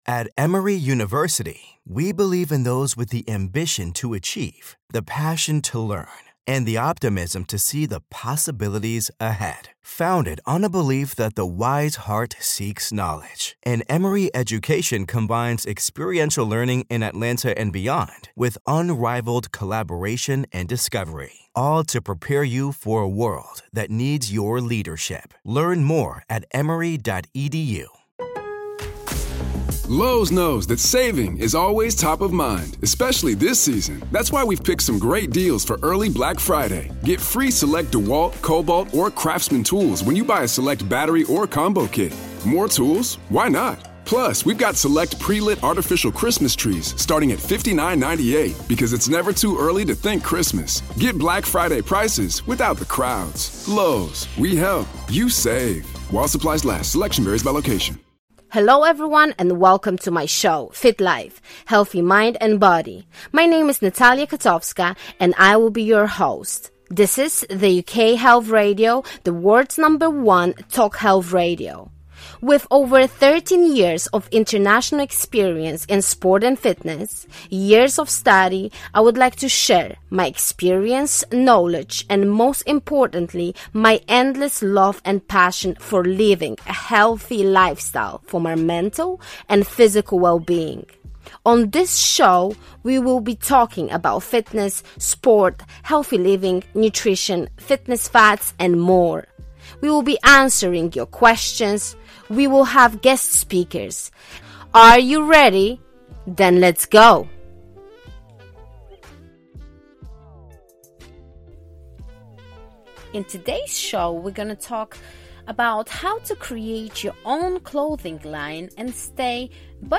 Your questions are answered by experts, and each episode includes group exercise.